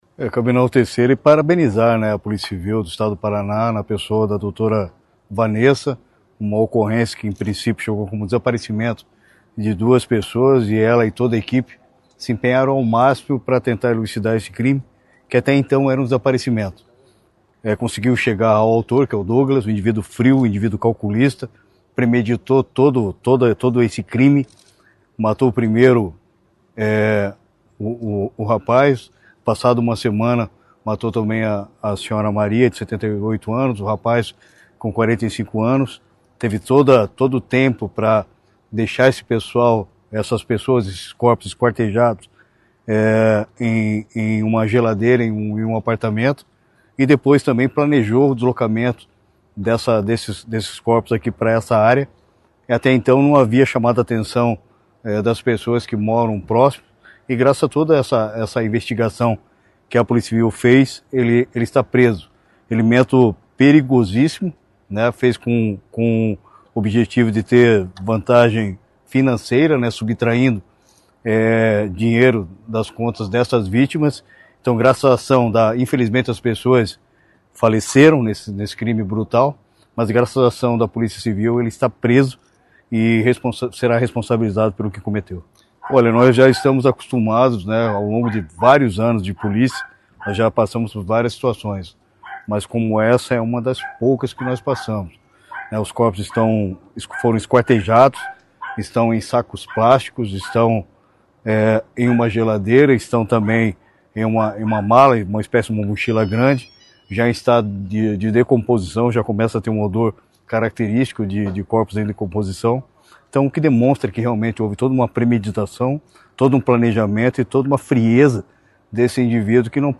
Sonora do secretário Estadual da Segurança Pública, Hudson Teixeira, sobre a elucidação de um latrocínio e ocultação da cadáveres na Região de Curitiba